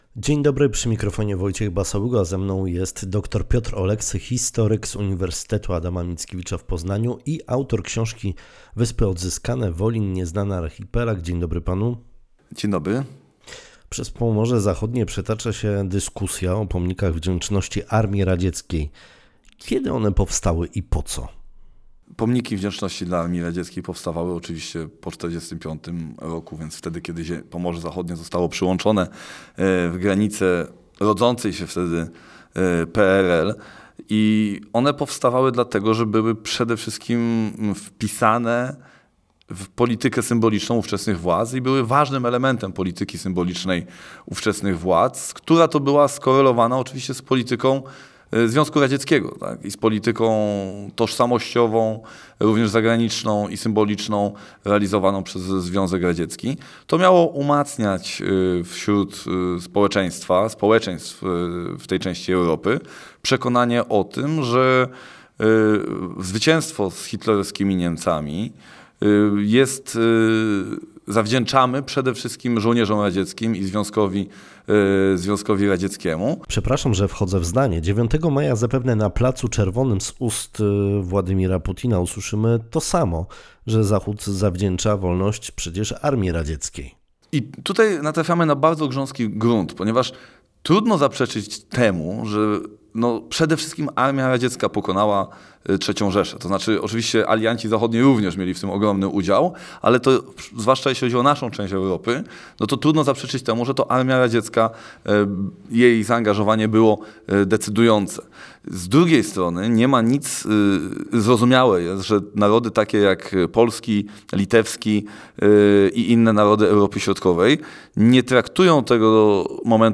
Przez Pomorze Zachodnie przetacza się dyskusja o pomnikach wdzięczności Armii Radzieckiej. Między innymi właśnie o to zapytaliśmy naszego dzisiejszego gościa Rozmowy Dnia